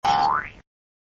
boing.ogg